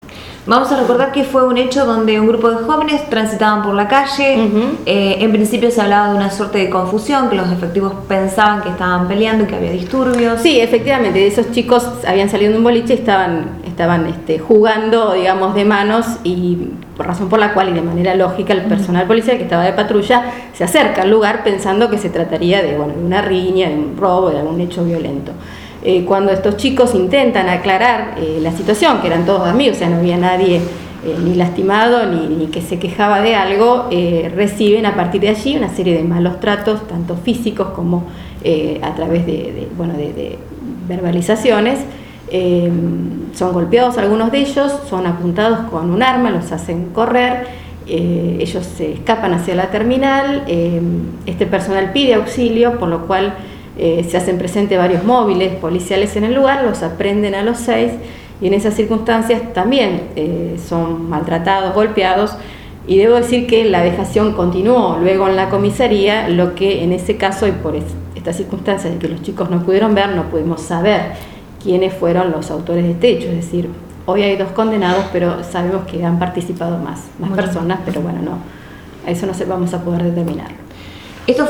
Audios fiscal Marcela Pérez: